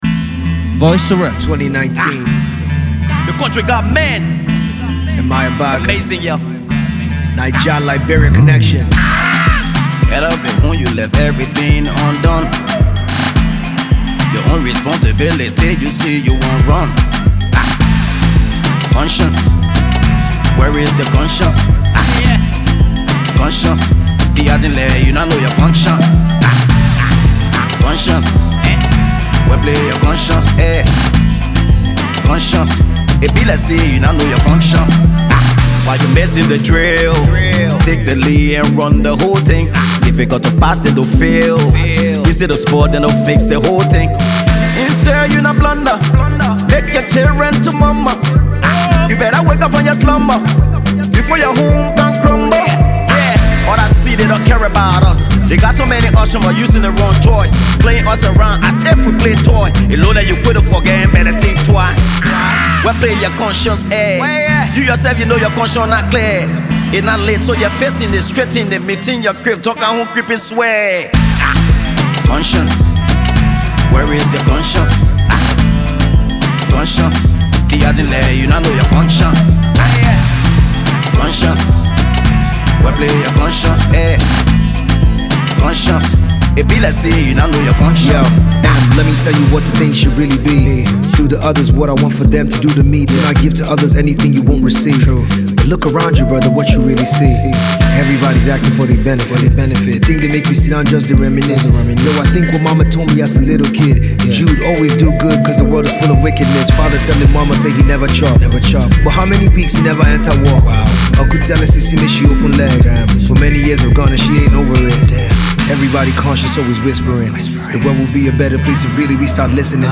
/ Hip-Co, Hip-Hop / By